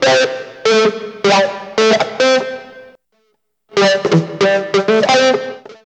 62 GUIT 2 -L.wav